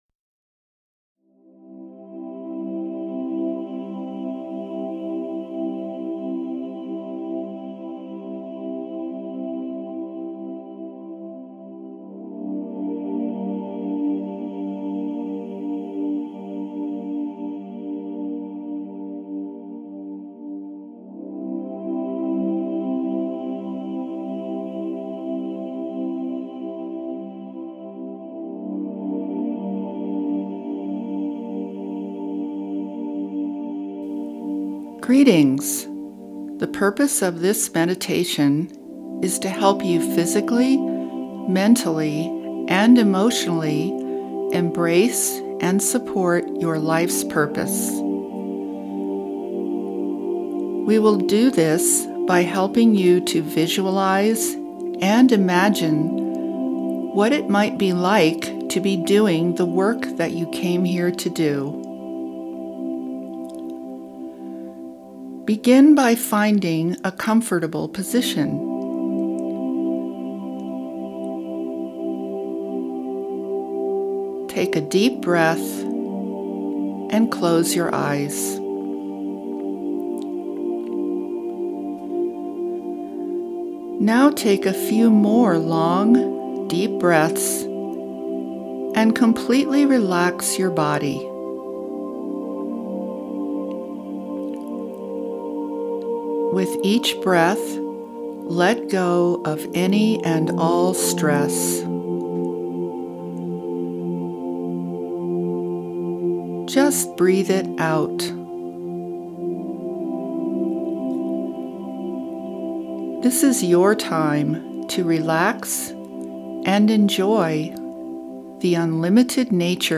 Download your life purpose e-book & guided meditation